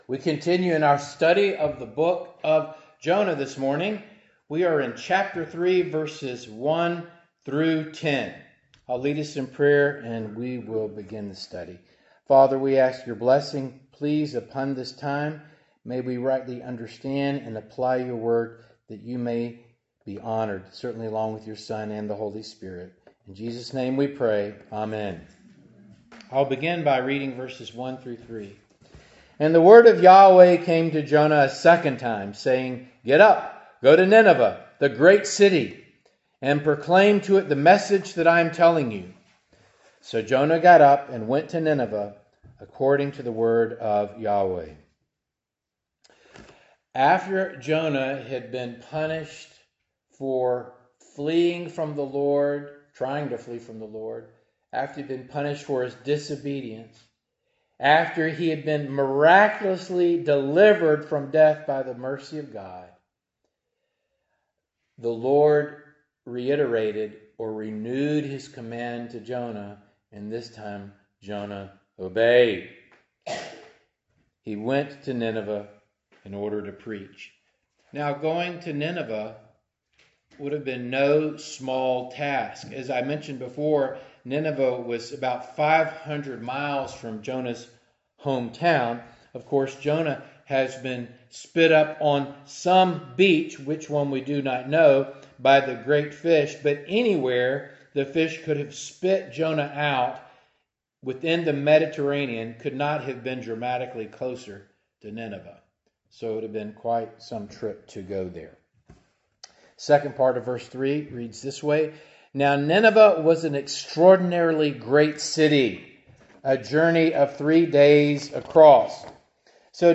Passage: Jonah 3:1-10 Service Type: Morning Service